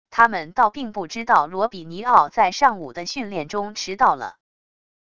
他们倒并不知道罗比尼奥在上午的训练中迟到了wav音频生成系统WAV Audio Player